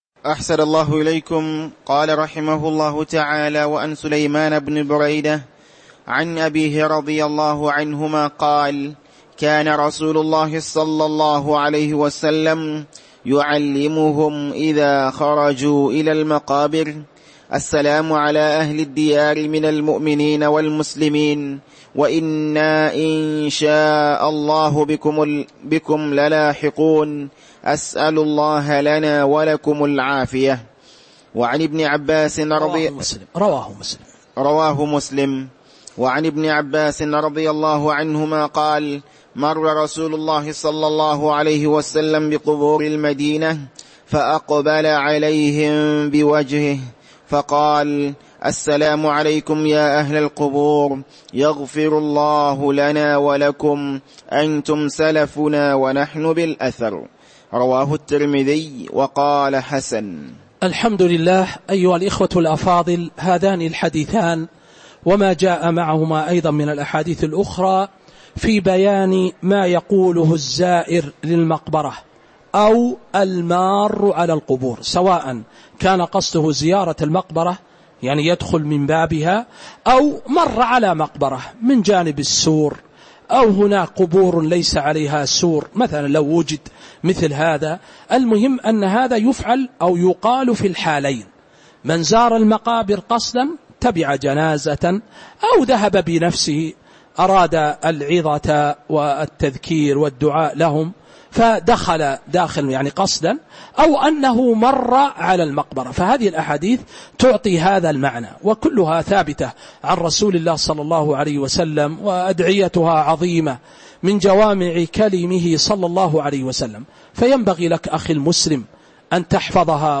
تاريخ النشر ٢٢ شعبان ١٤٤٥ هـ المكان: المسجد النبوي الشيخ